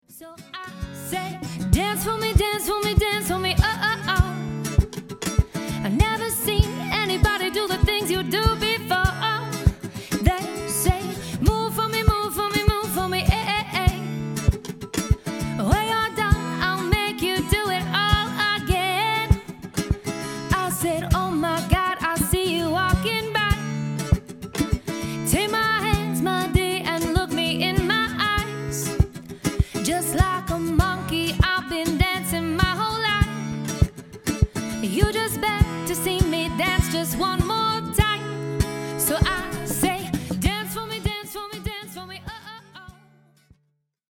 Harmony Vocals | Keyboards/Guitar | Duo/Trio option | MC